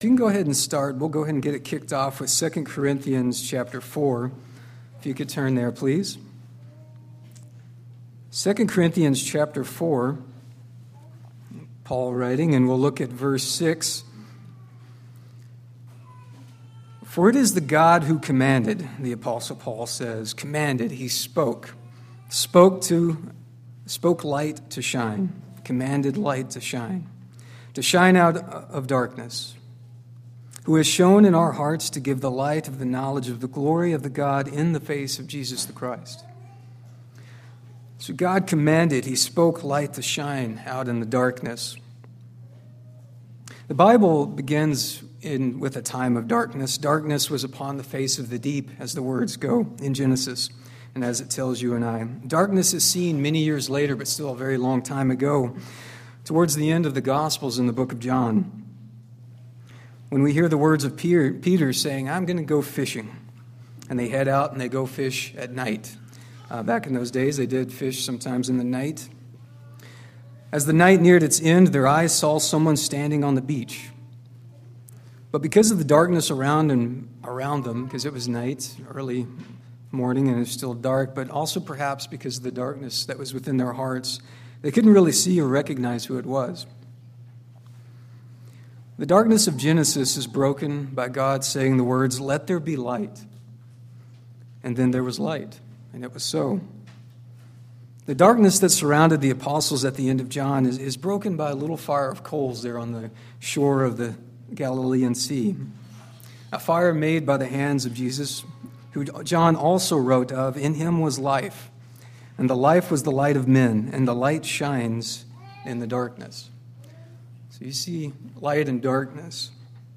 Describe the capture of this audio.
Given in Kennewick, WA Chewelah, WA Spokane, WA